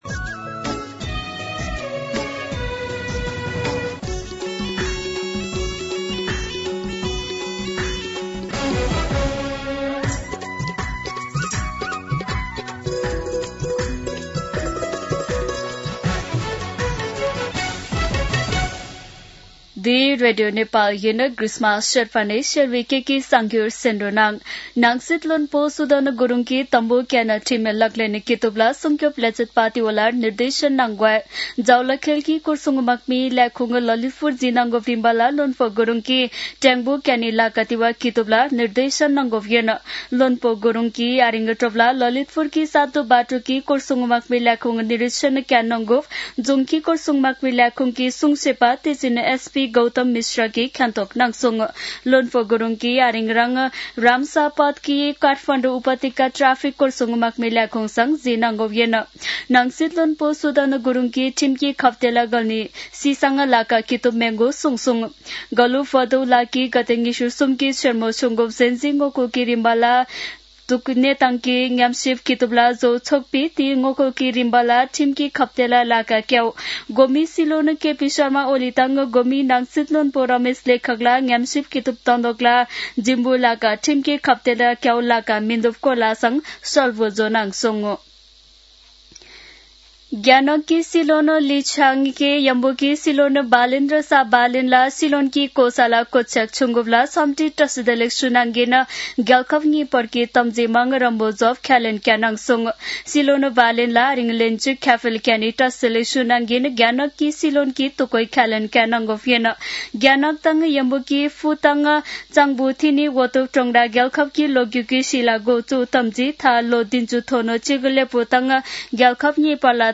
शेर्पा भाषाको समाचार : १४ चैत , २०८२
Sherpa-News-14.mp3